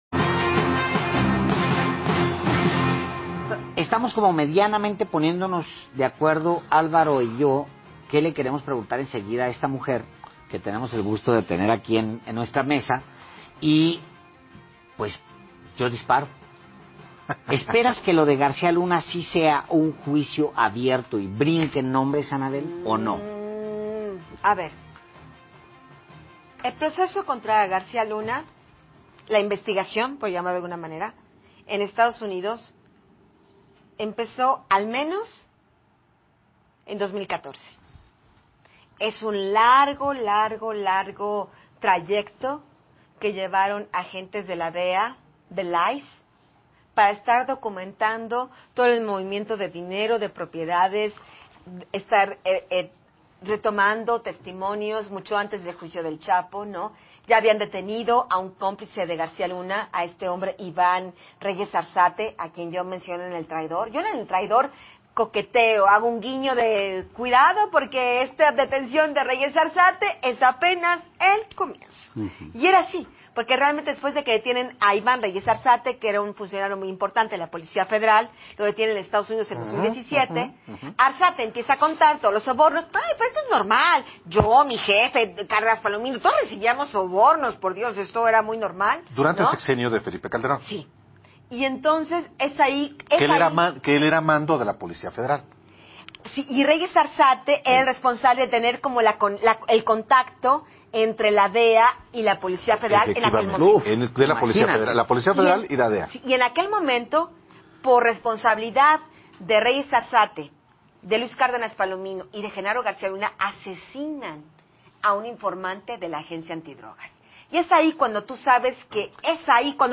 Así lo afirmó la periodista Anabel Hernández en el programa ‘Los Periodistas’, de la cadena La Octava, donde reveló que según sus fuentes, García Luna y el Mandatario durante el periodo 1988-1994 sostenían encuentros privados en la residencia de este último, cuando el denominado “super policía” trabajaba para Calderón Hinojosa.